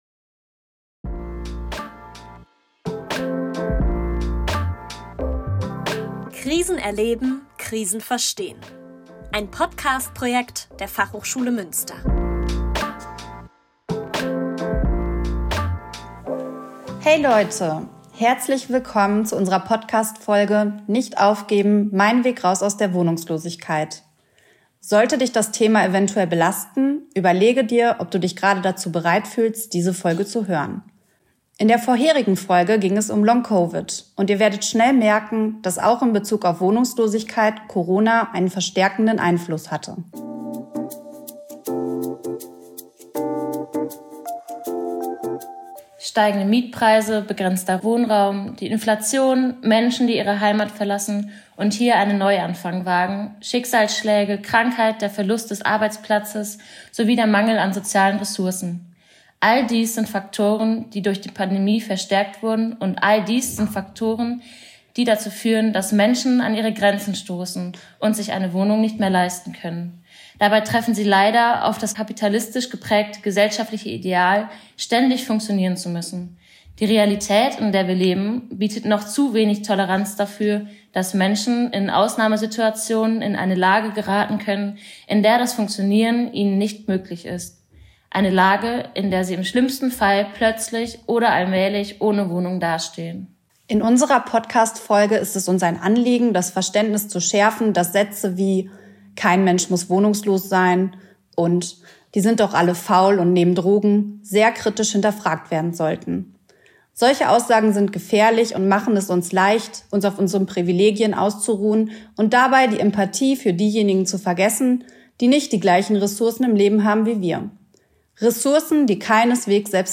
Dieses Interview bietet nicht nur Einblicke in die Realität der Wohnungslosigkeit, sondern zeigt auch auf, wie wichtig es ist, gemeinsam Möglichkeiten zu finden, um Menschen in schwierigen Lebenssituationen zu unterstützen.